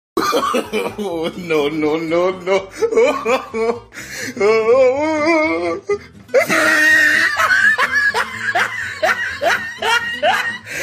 Sound Effects
Oh No No No Laugh